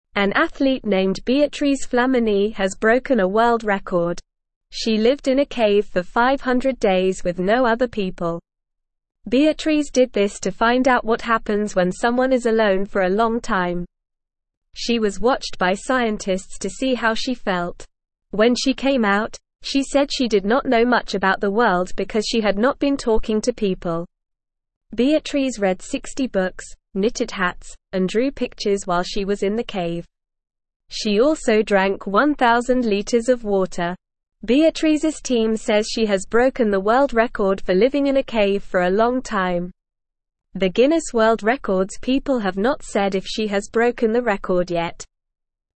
English-Newsroom-Beginner-NORMAL-Reading-Woman-Lives-in-Cave-for-500-Days.mp3